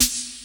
COOL SNR.wav